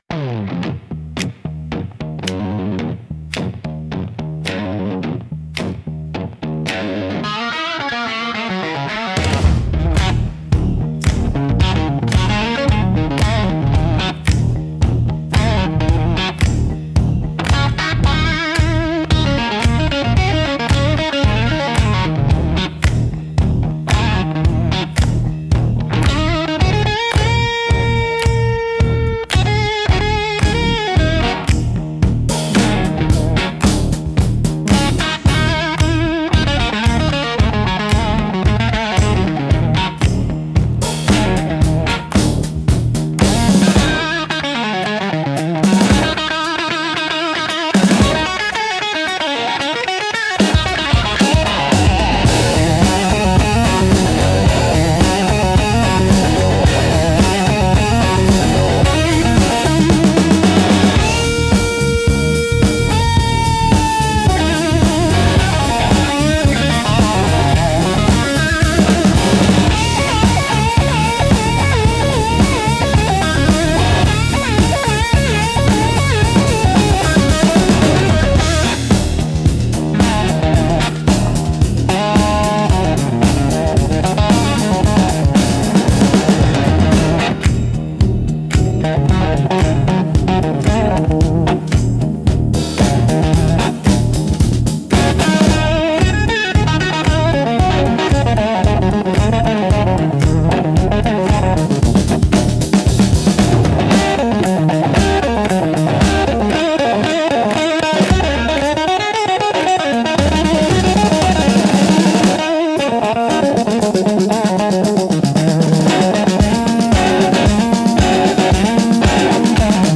Instrumental - Dirt Road Lament in D Minor